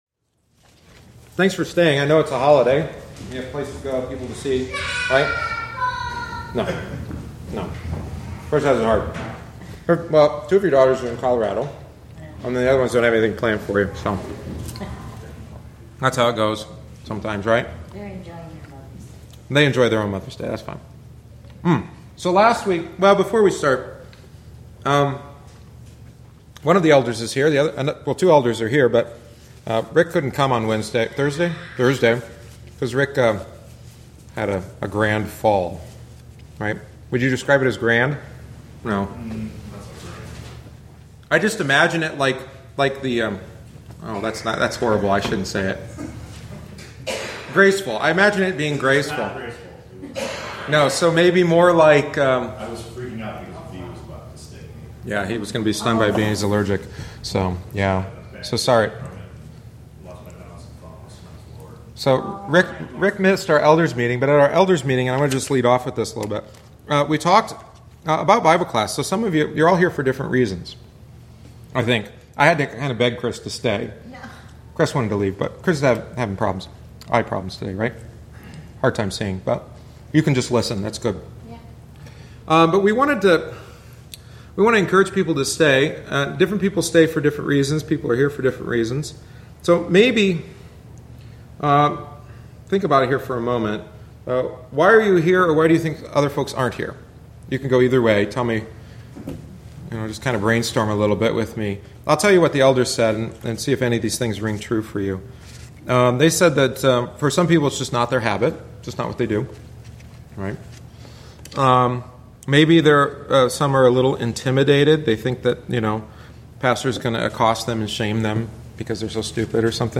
The following is the thirtieth week’s lesson.